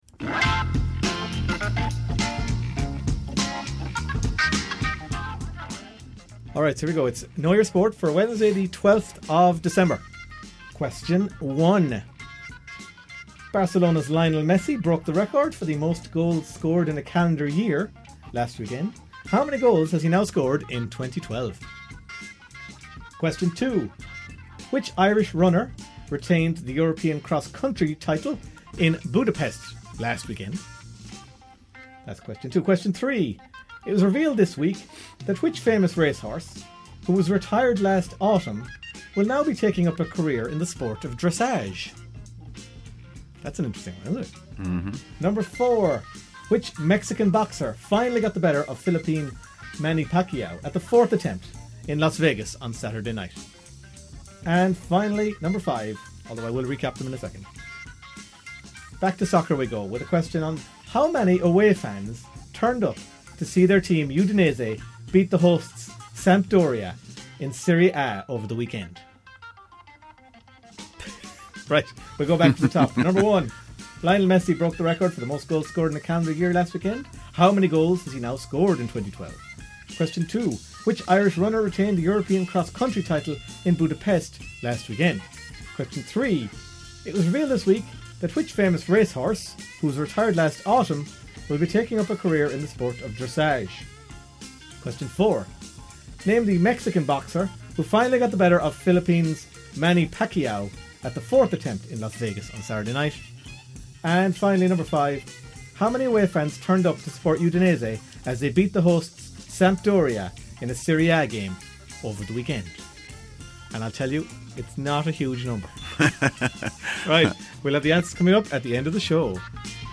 Five sports trivia questions from the Half-time Team Talk show on Claremorris Community Radio.